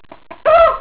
Hen 2
HEN_2.wav